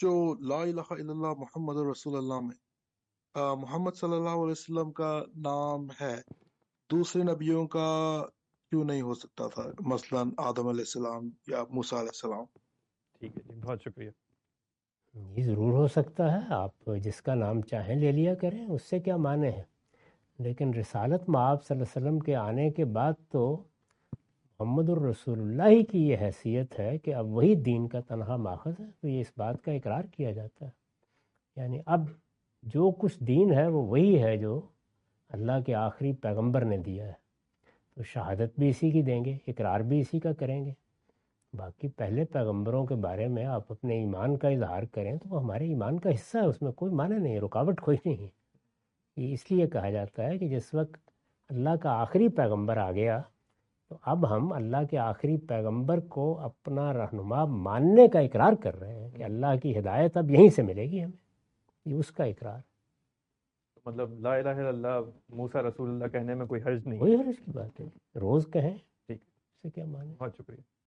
Category: Reflections / Questions_Answers /
In this video, Mr Ghamidi answer the question about "Can someone else's name be taken instead of "Muhammad, the Messenger of Allah (PBUH)" in the declaration of faith?".